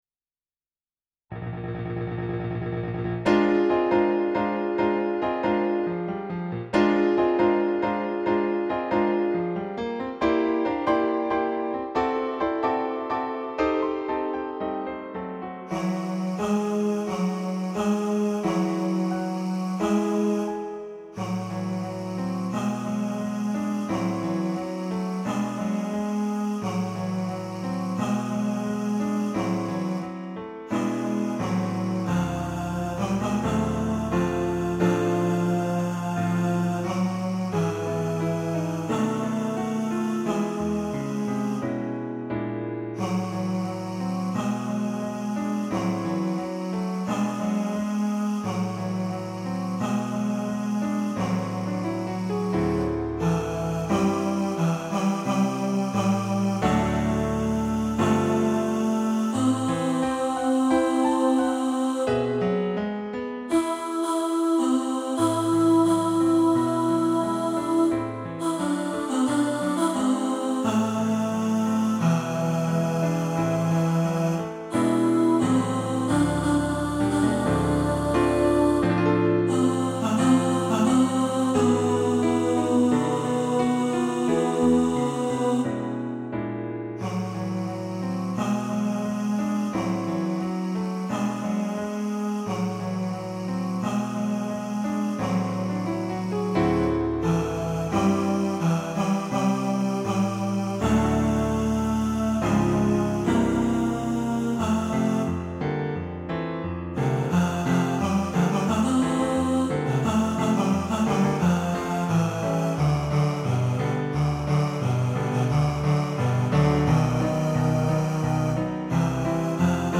Porgy-And-Bess-Tenor.mp3